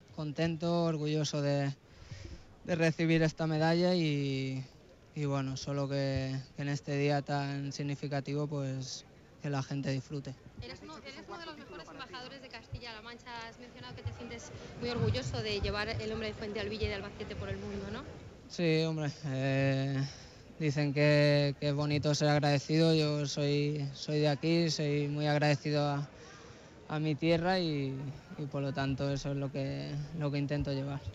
JCCM Domingo, 31 Mayo 2009 - 2:00am El futbolista de Fuentealbilla, Andrés Iniesta, realizó una atención a los medios de comunicación, tras el acto institucional del Día de Castilla-La Mancha, para reafirar su satisfacción y orgullo por ser Medalla de Oro de la Región 2009, por su trayectoria profesional y dijo sentirse orgulloso y agradecido a esta tierra. iniesta0.mp2